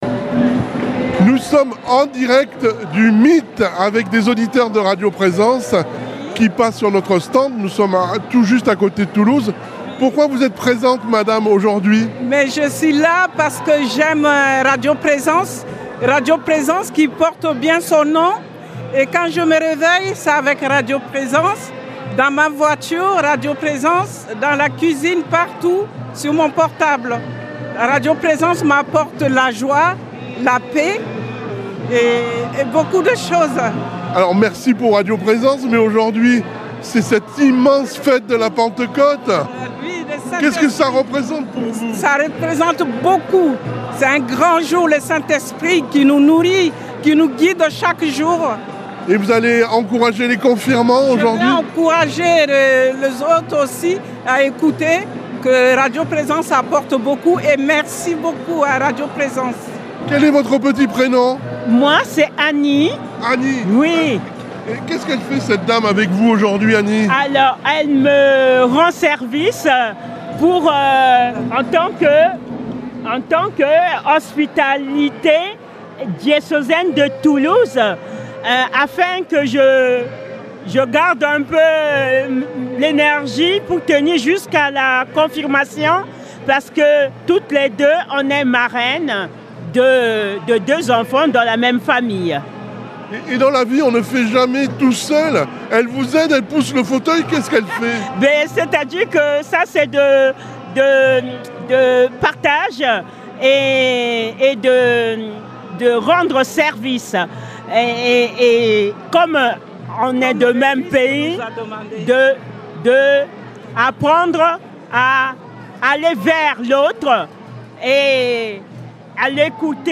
Ils témoignent de leur attachement à la station, de ce qu’ils y trouvent, et de la joie de se retrouver en Église. Une mosaïque de voix, spontanée et chaleureuse, qui fait résonner l’âme de Radio Présence.